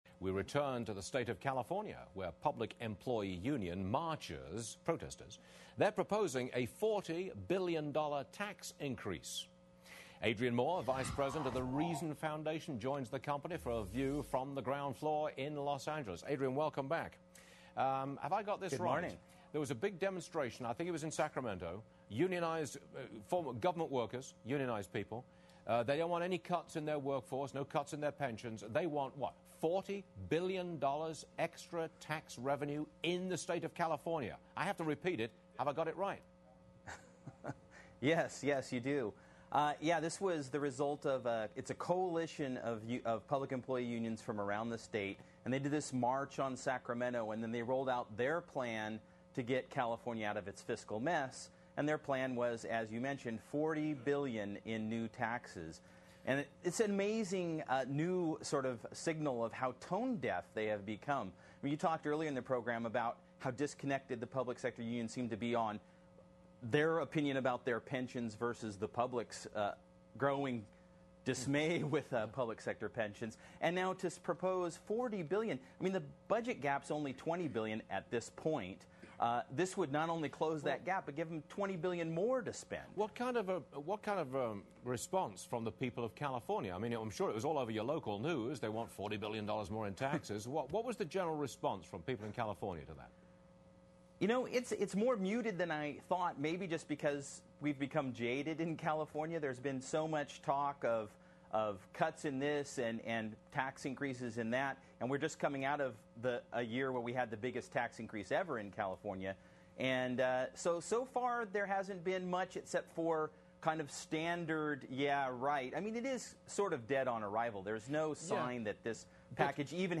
talked with Stuart Varney on Fox Business's Varney & Company about California's public sector unions.